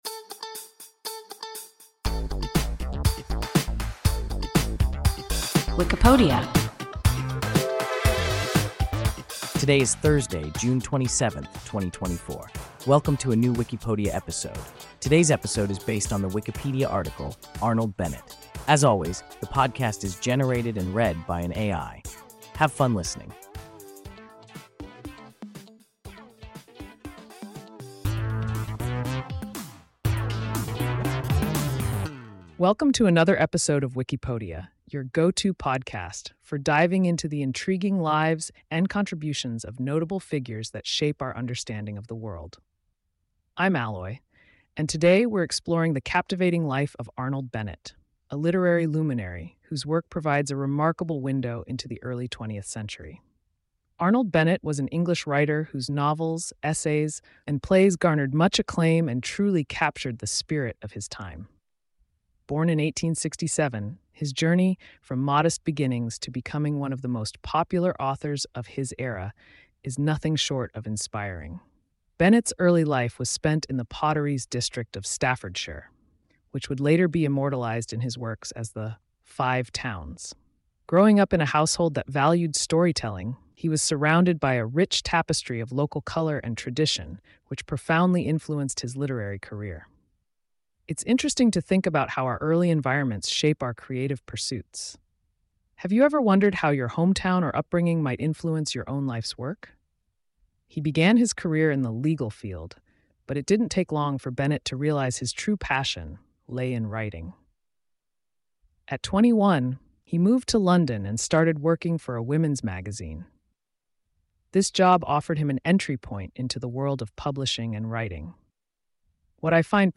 Arnold Bennett – WIKIPODIA – ein KI Podcast